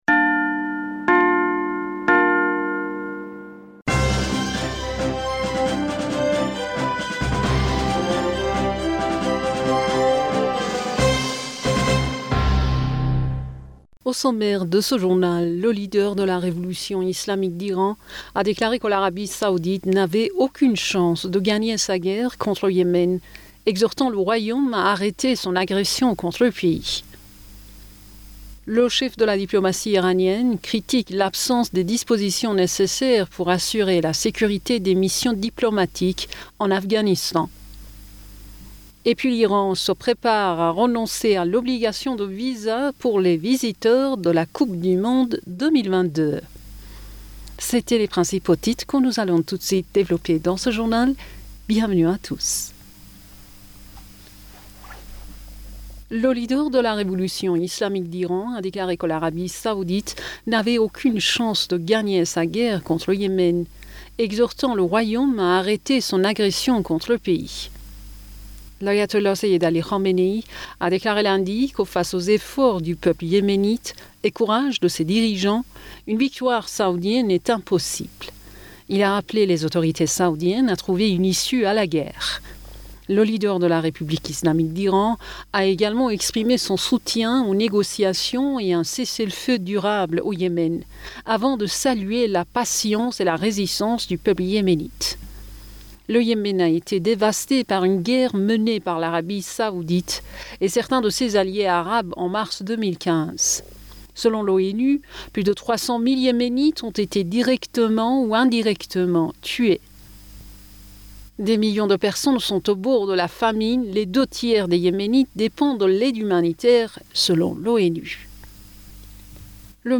Bulletin d'information Du 13 Avril 2022